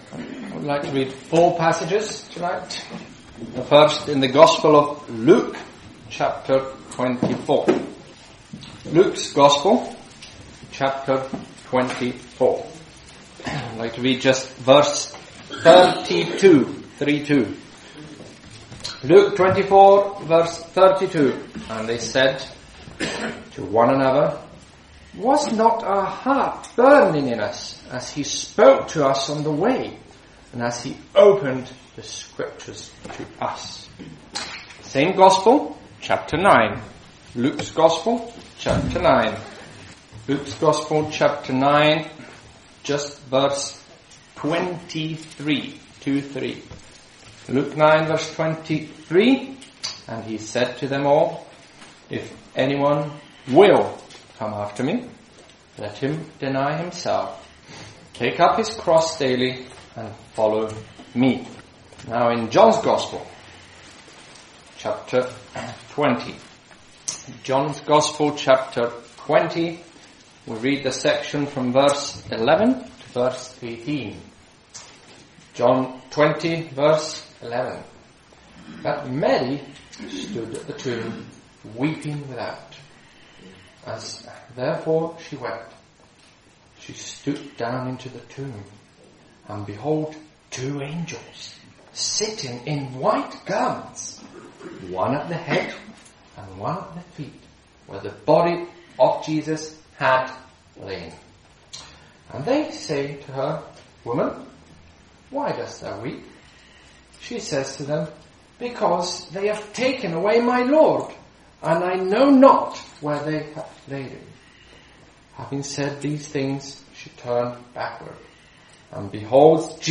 Commitment to the Lord Jesus - Croydon Address, UK